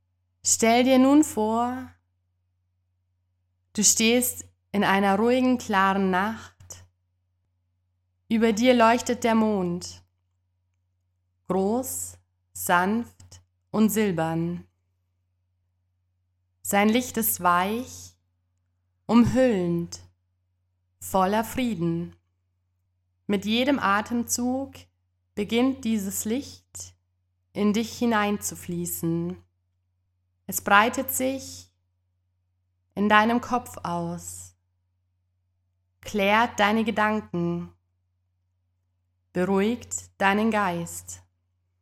• Format: Geführte Meditationen (Audio-Dateien)